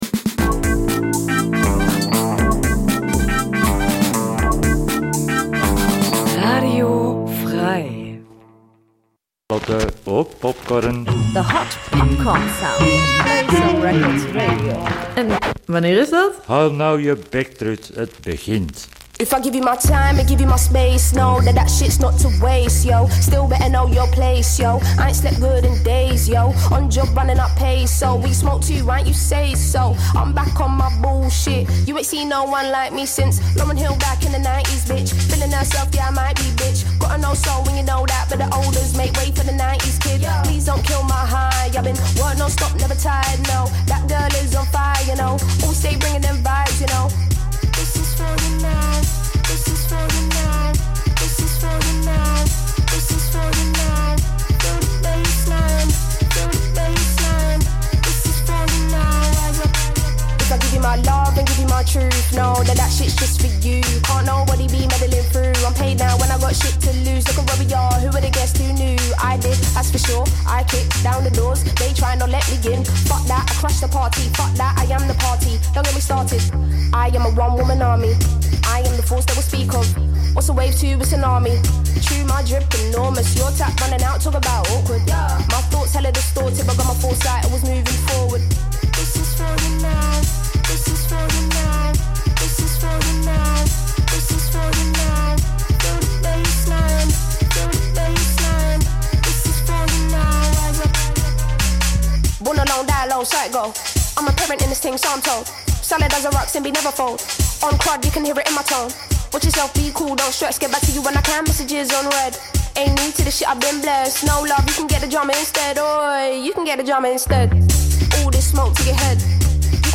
ROCKABILLY der alten schule, jamaikanischer SKA, wilder RHYTHM�N�BLUES, zuckers��er ROCKSTEADY, hei�er ROCK�N�ROLL, karibischer CALYPSO, stampfender NORTHERN SOUL, early REGGAE und leicht gesalzenes POPCORN bilden das Grundrezept. Je nach Laune und dem jeweiligen Schallplattenunterhalter(n) wird eventuell mit Psychobilly, Punkrock, 2Tone, DooWop, Blues sowie Jazz verfeinert oder auch nicht, solang es den Ohren schmeckt.